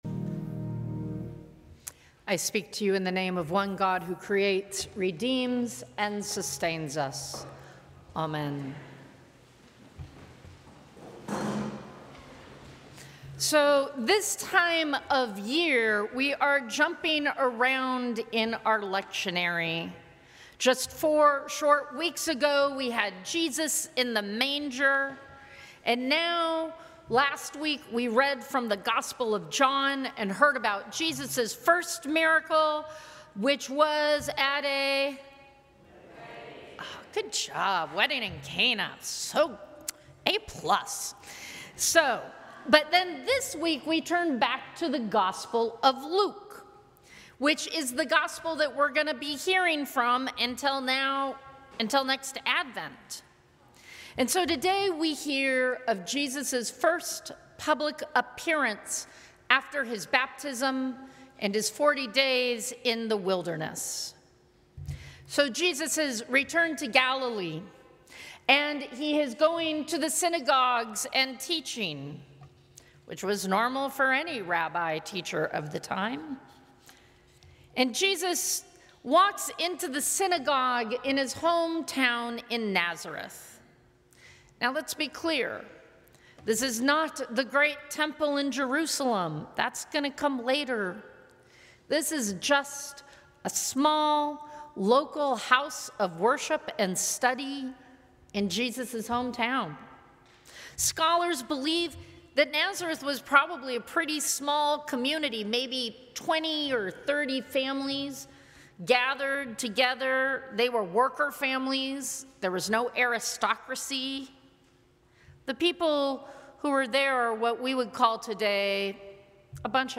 Sermons from St. Cross Episcopal Church Third Sunday after the Epiphany Jan 26 2025 | 00:12:27 1x 00:00 / 00:12:27 Subscribe Share Apple Podcasts Spotify Overcast RSS Feed Share Link Embed